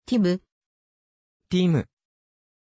Aussprache von Tim
pronunciation-tim-ja.mp3